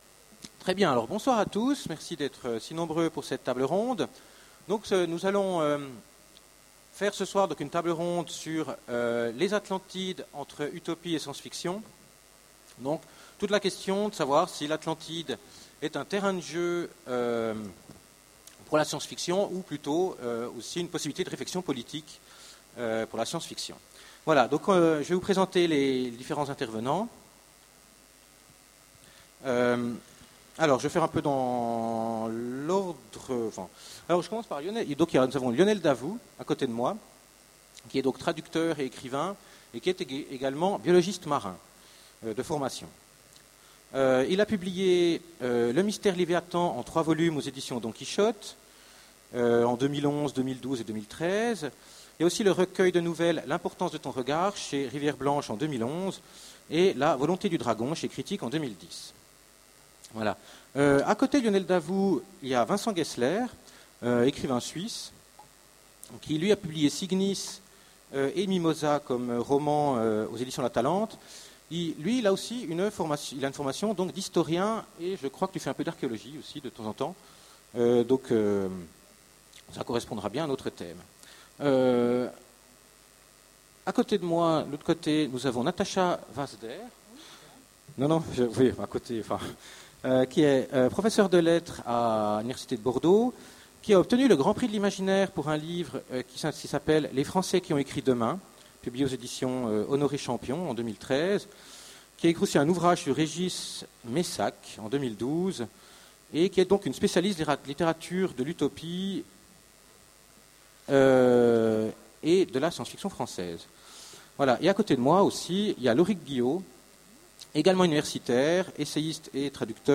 Utopiales 13 : Conférence Les Atlantides : entre utopies et science-fiction